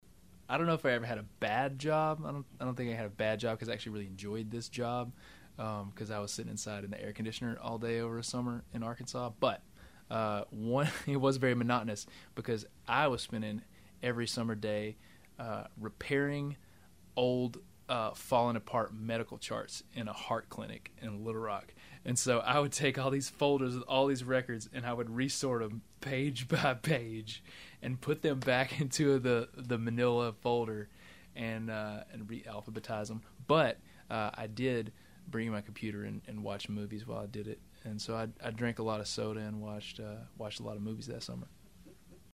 Audio / Adam Hambrick talks about one of his summer jobs when he was growing up in Arkansas.